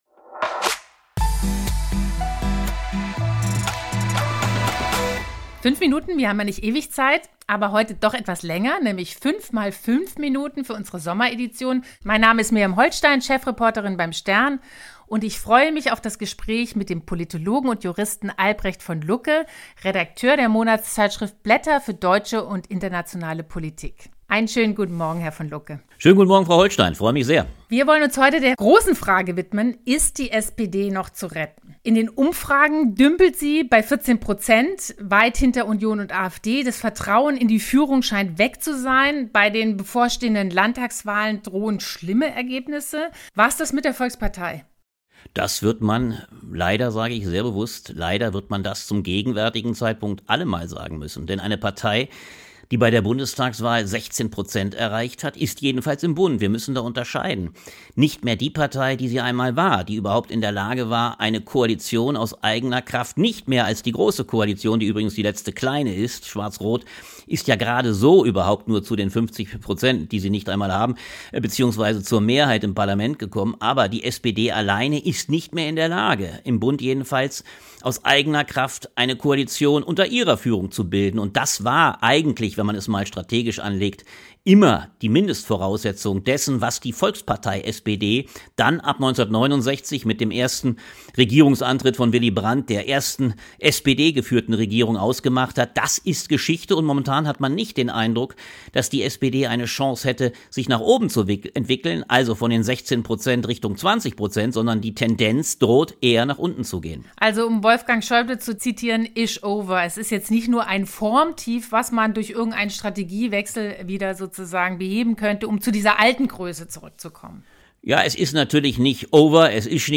Was also tun?Ein Podcast-Interview, das diesmal nicht nur fünf Minuten dauert, wie sonst beim "5-Minuten-Talk".